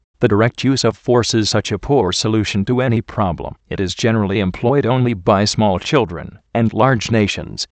Location: USA
How do you pronounce this word?